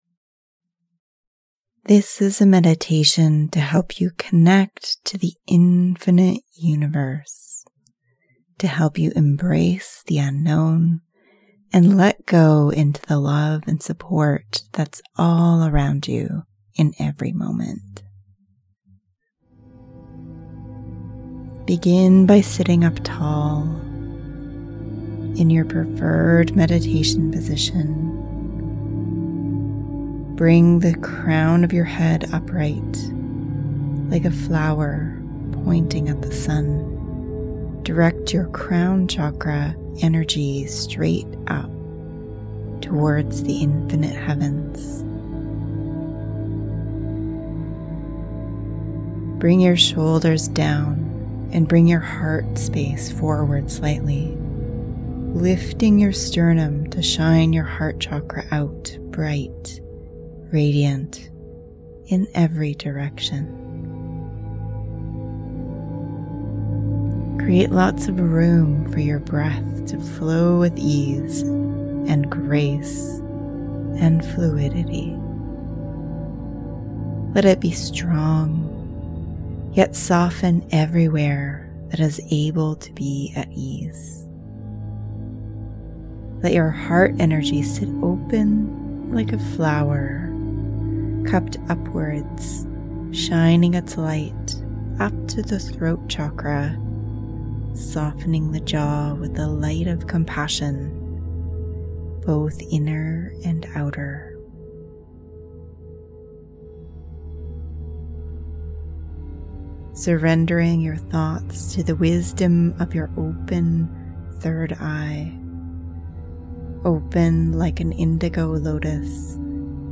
This meditation is designed to help you connect with the vast love in the cosmos and find a sense of peace and calm.